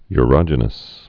(y-rŏjə-nəs)